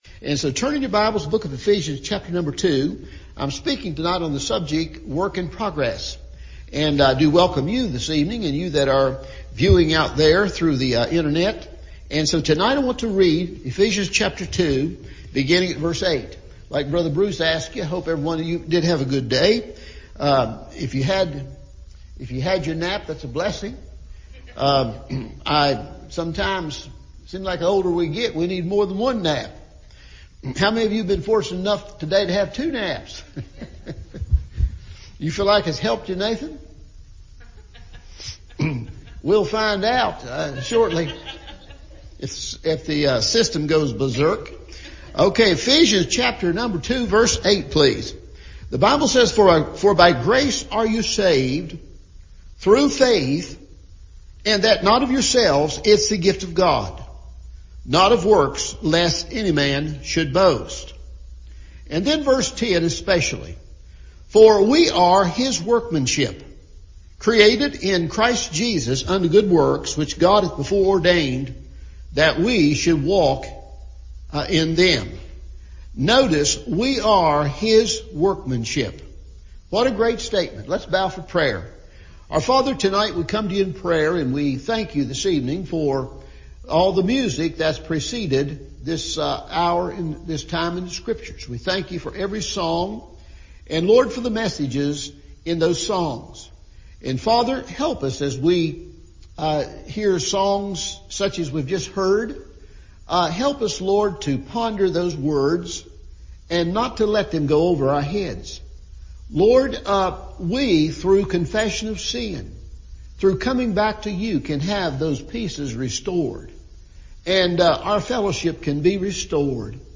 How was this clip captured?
Work in Progress – Evening Service